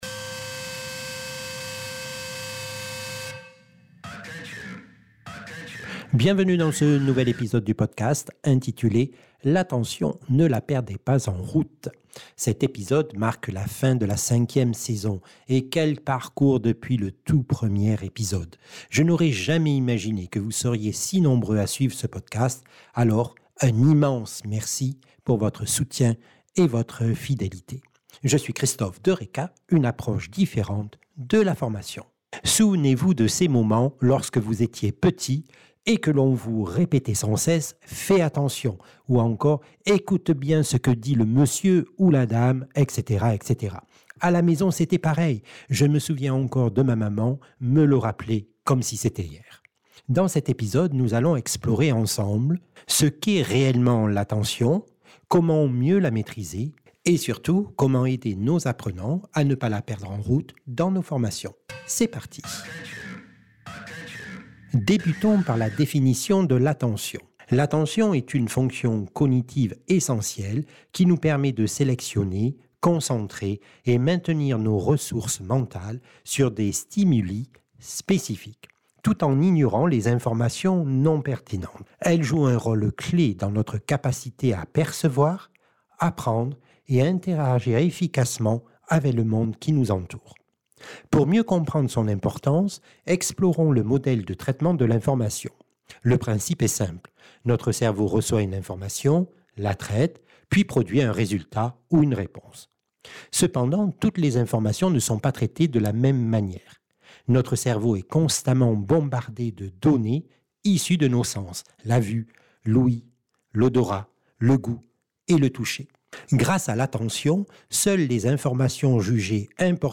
(Extrait musical de ce podcast)